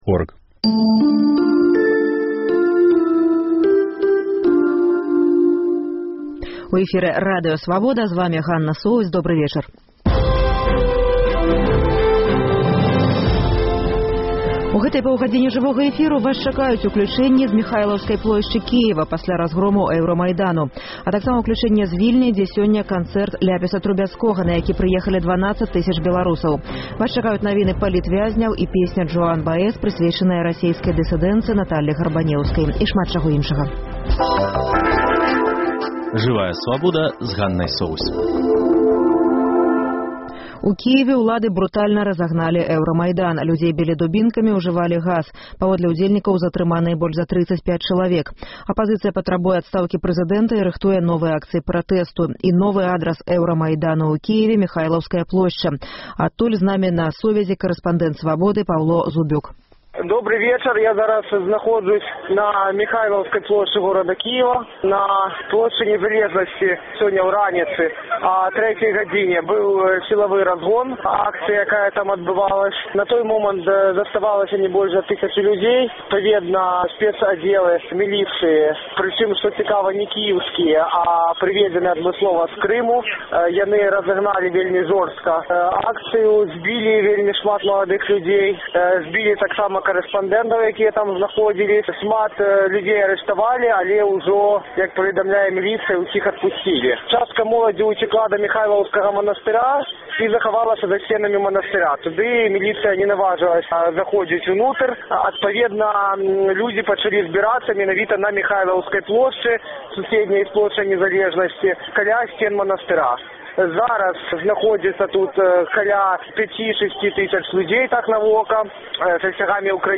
Сёньня ў праграме: У Кіеве ўлады брутальна разагналі Эўрамайдан. Апазыцыя патрабуе адстаўкі прэзыдэнта і рыхтуе новыя акцыі пратэсту. Рэпартаж з Кіеву і ацэнкі палітоляга.
Жывое ўключэньне з канцэрту.